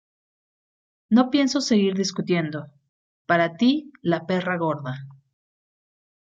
For you the fat bitch Read more Noun Adj 🐕 perro Noun Adj Read more Frequency A2 Hyphenated as pe‧rra Pronounced as (IPA) /ˈpera/ Etymology Feminine of perro (“dog”).